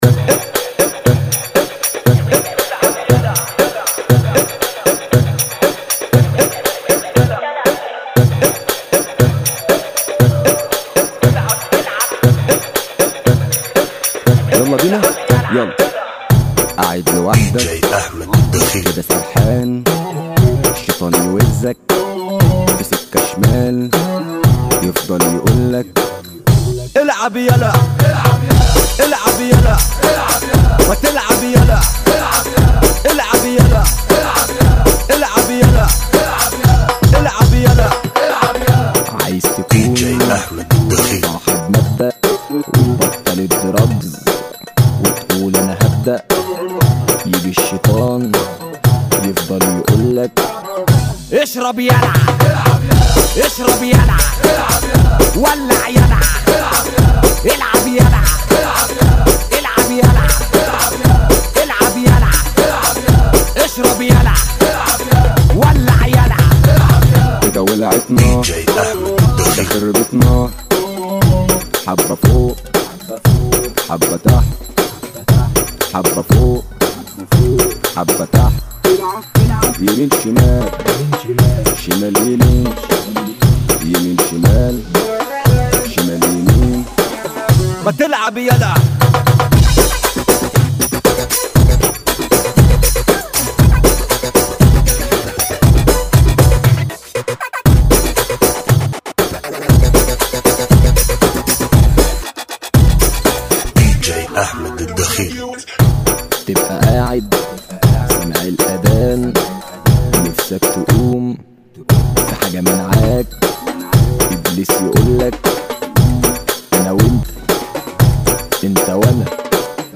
ريمكس
Funky Remix